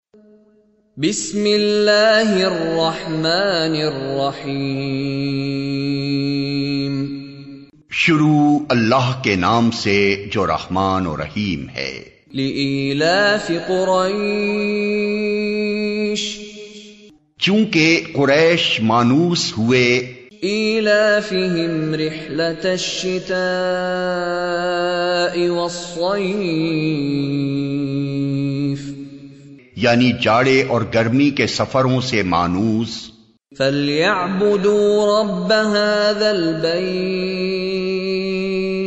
Surah Quraish Beautifull Recitation MP3 Download By Abdur Rahman Al Sudais in best audio quality.
Surah Quraish with Urdu Translation by Sheikh Mishary Rashid.MP3